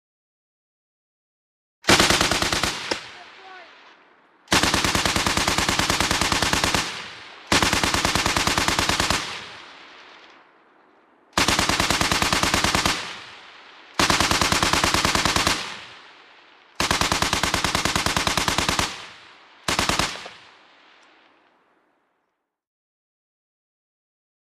MachGunSquadAutoBu PE706202
WEAPONS - MACHINE GUNS HEAVY SQUAD AUTOMATIC WEAPON: EXT: Long bursts closer, voices at beginning.